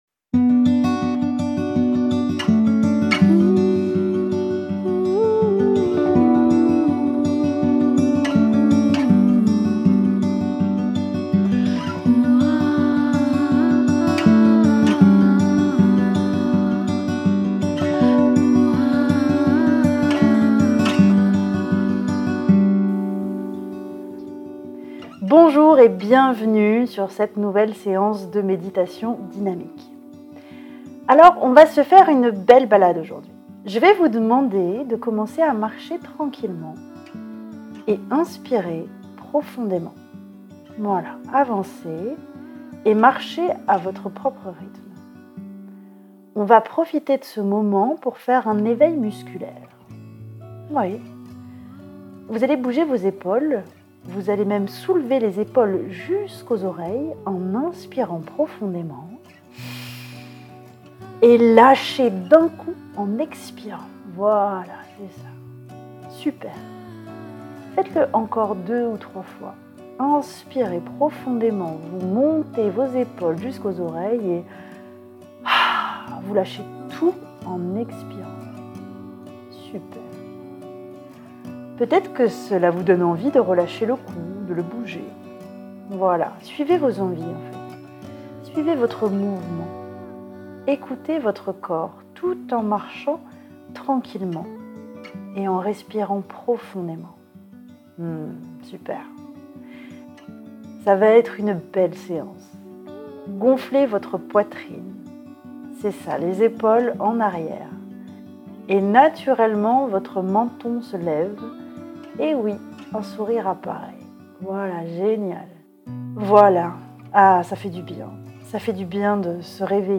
Méditation dynamique